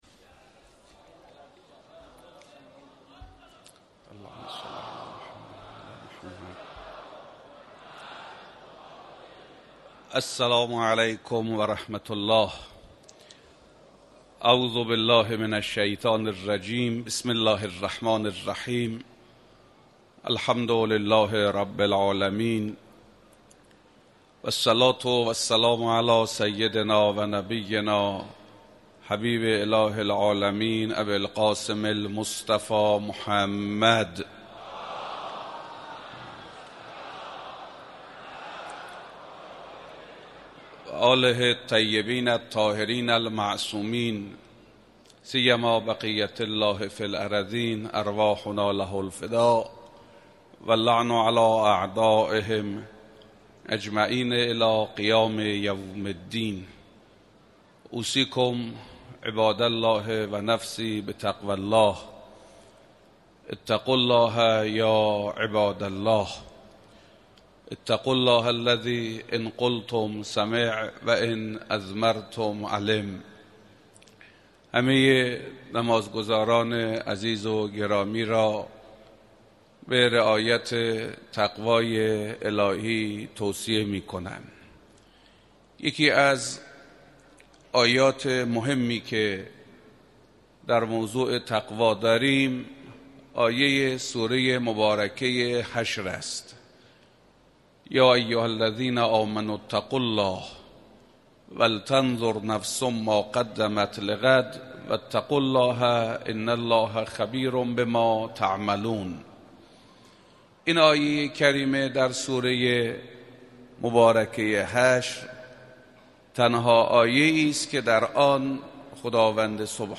نمازجمعه 9 مرداد در مصلای امام خمینی(ره)اهواز به امامت حجت الاسلام حسن زاده امام جمعه موقت اهواز برگزار شد.